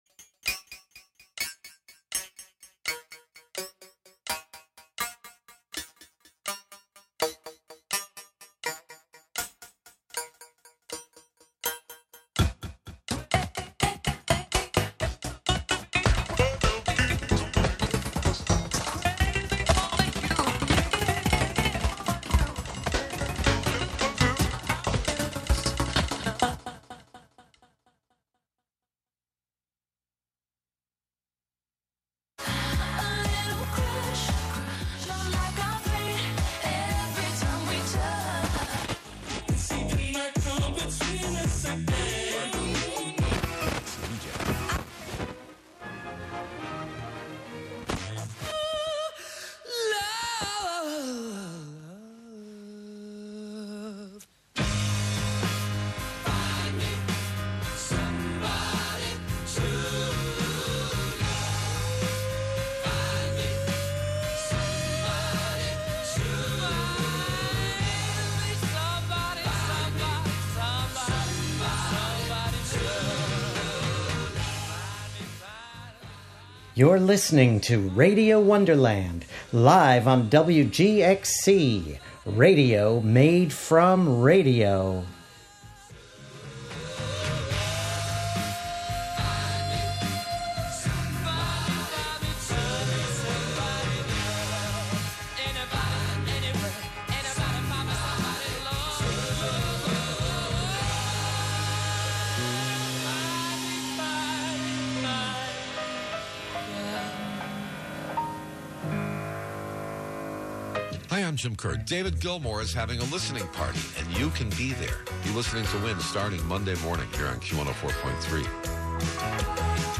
11am Live from Brooklyn, New York
making instant techno 90 percent of the time
play those S's, T's and K's like a drum machine